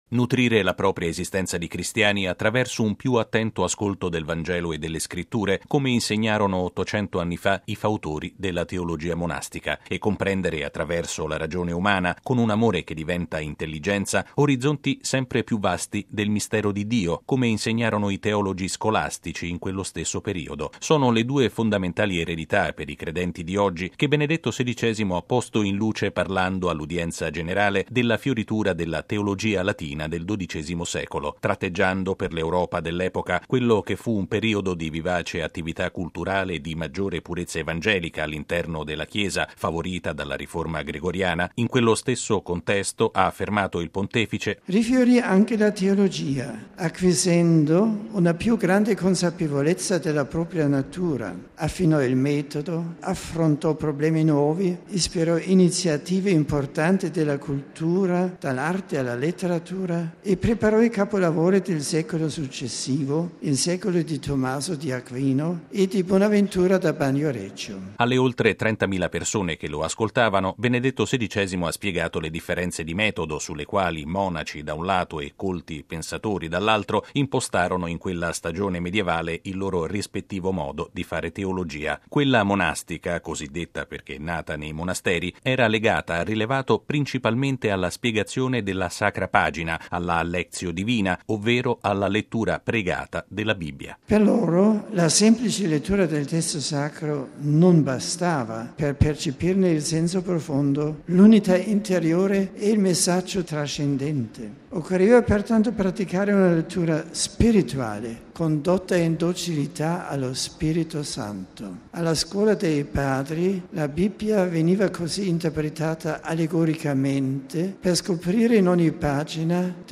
L’affermazione di Giovanni Paolo II è stata ripresa da Benedetto XVI a conclusione dell’udienza generale di questa mattina in Piazza San Pietro. Il Papa ha dedicato la sua catechesi ai due modelli di teologia, quella monastica e quella scolastica, che si affermarono nel XII secolo in Europa, grazie a una felice congiuntura sociale e culturale.